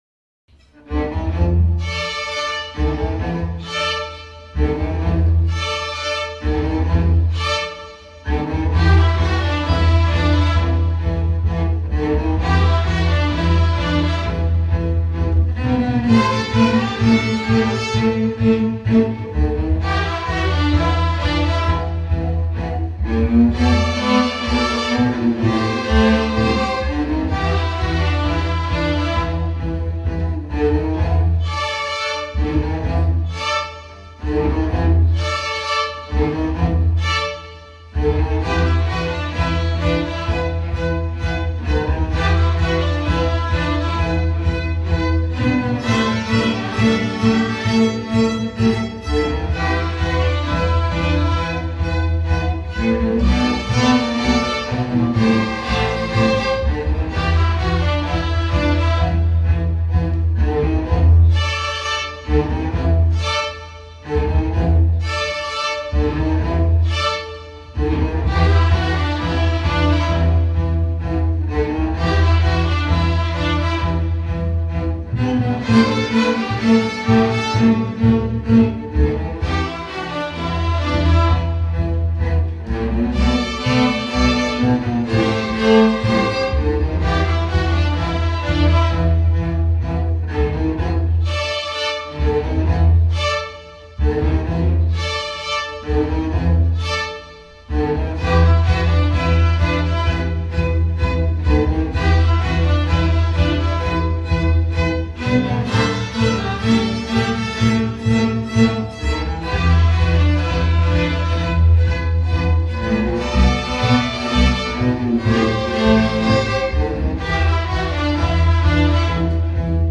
Orquestra de Camara Projeto Guri Orquestra de camara do projeto guri bebedouro